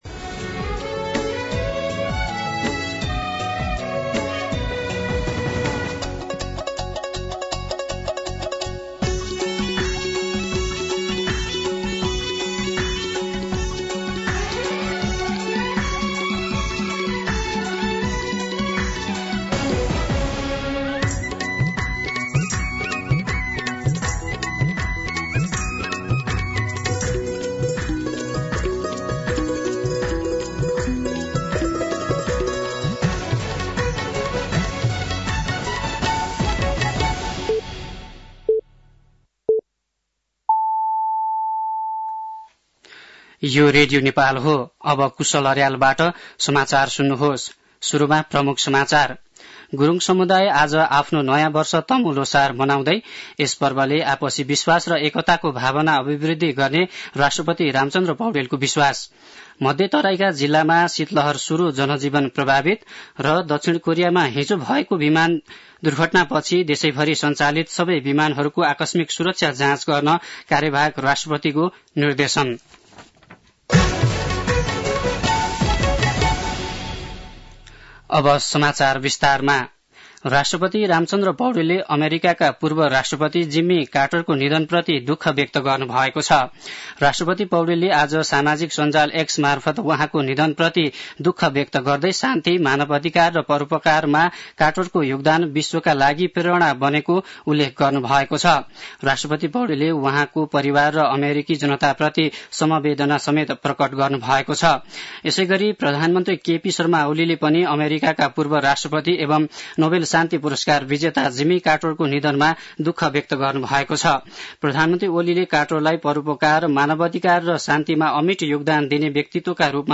An online outlet of Nepal's national radio broadcaster
दिउँसो ३ बजेको नेपाली समाचार : १६ पुष , २०८१
News-3-pm-9-15.mp3